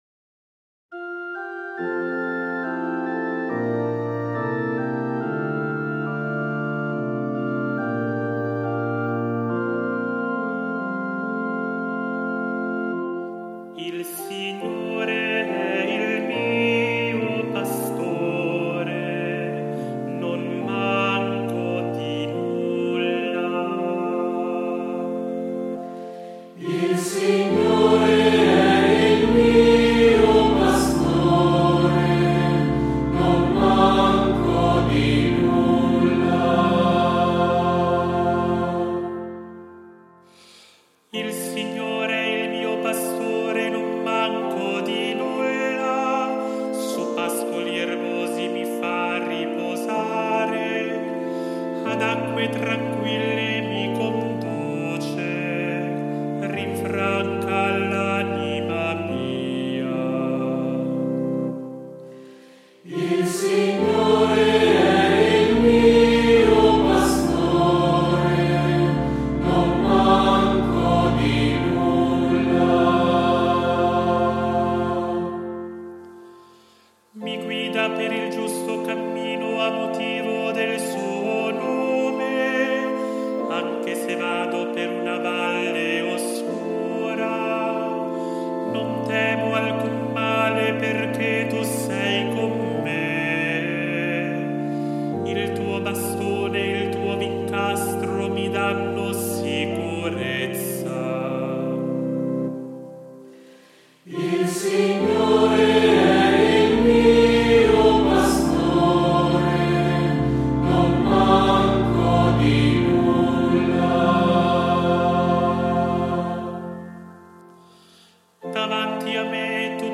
IL SALMO RESPONSORIALE